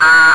Digital Horn Sound Effect
Download a high-quality digital horn sound effect.
digital-horn.mp3